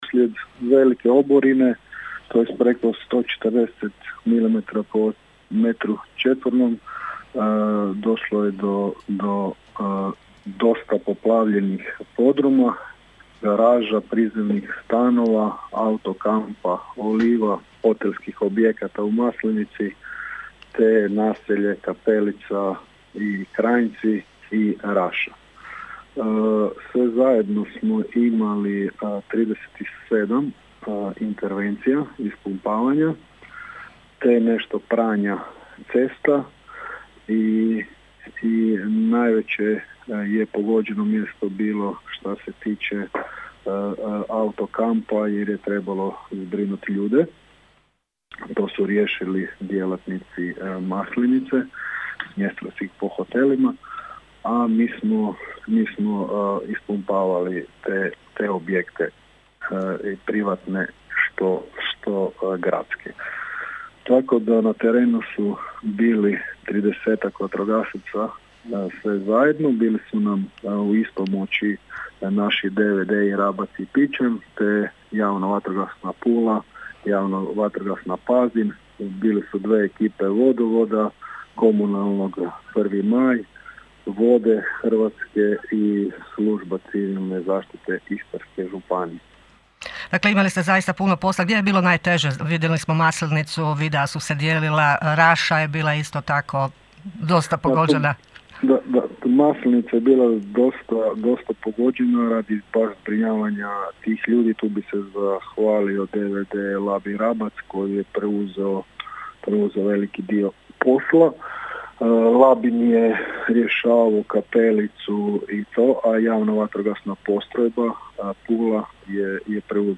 Zbog obilne kiše koja je jučer pala na dijelu Labinštine, prije svega u Raši, Rapcu, Kapelici, Labinu i Kranjcima vatrogasci su imali pune ruke posla. O jučerašnjem  nevremenu razgovaramo